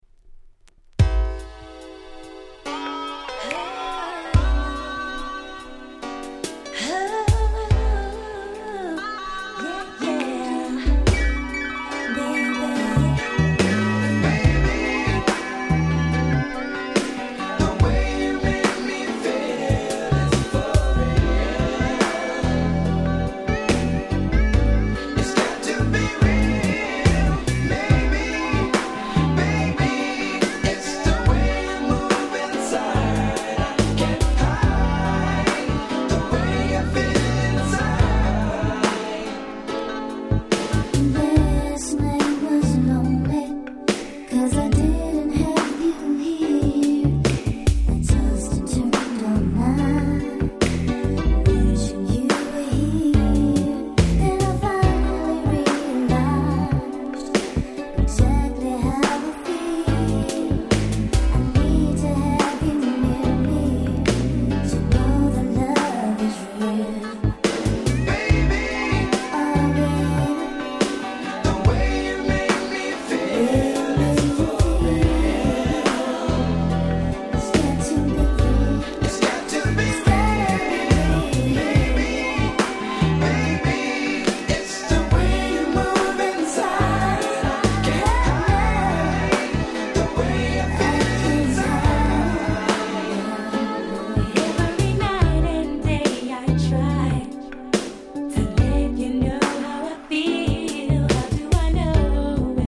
93' Nice R&B LP !!